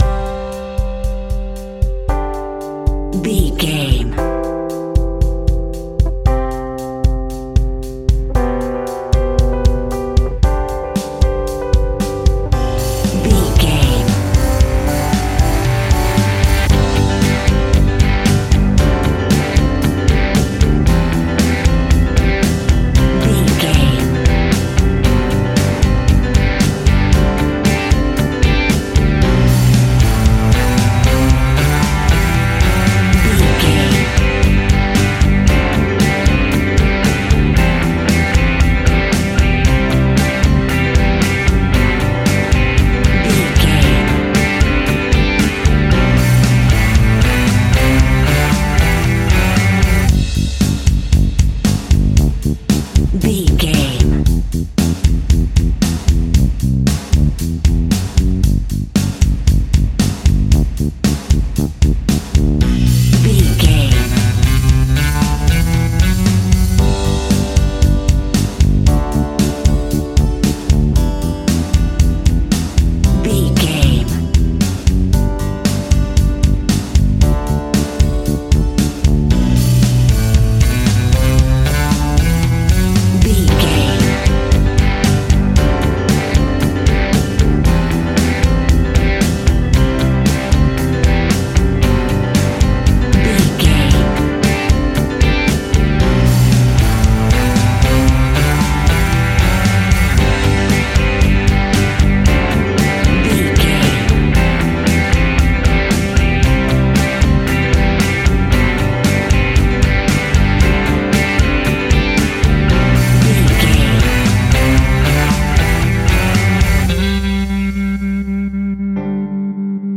Ionian/Major
fun
energetic
uplifting
acoustic guitars
drums
bass guitar
electric guitar
piano
organ